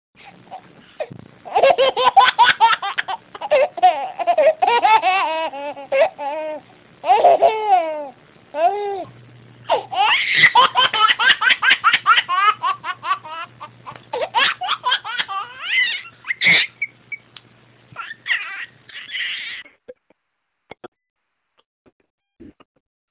Cute Baby Laughing ringtone free download